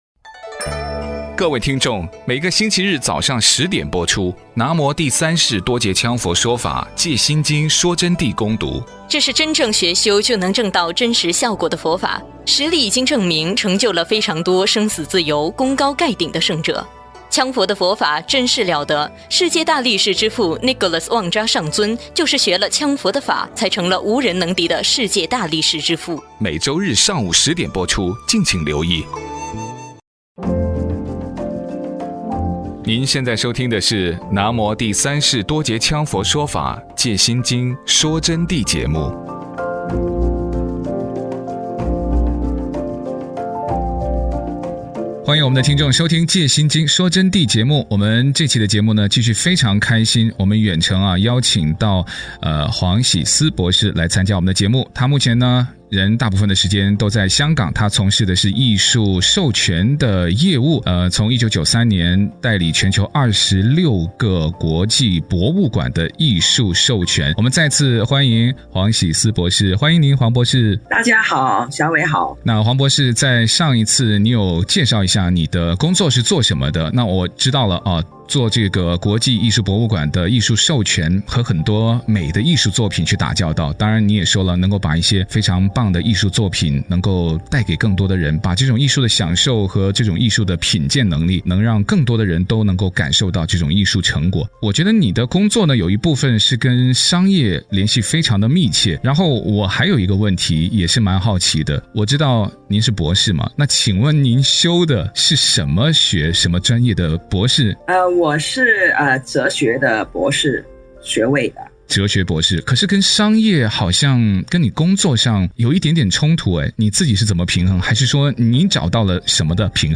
佛弟子访谈